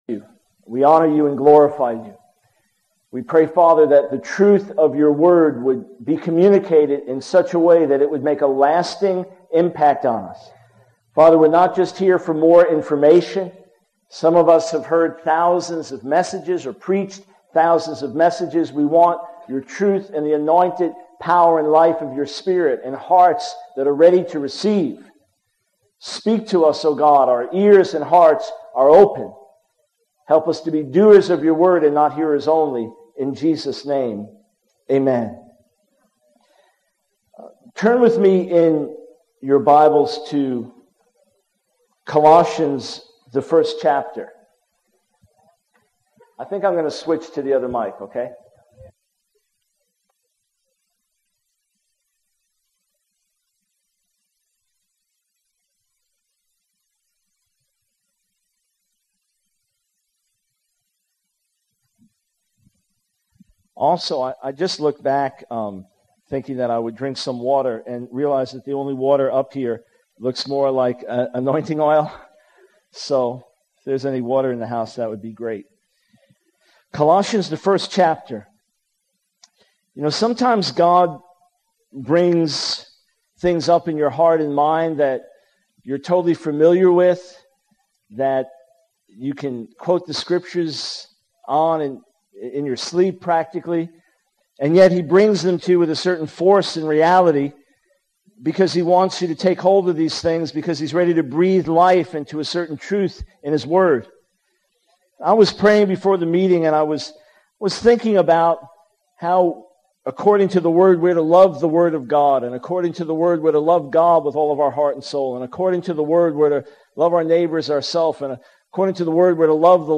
The sermon concludes with the speaker urging the audience to strive to be everything God is calling them to be, while recognizing the need to rely on the power of the Holy Spirit.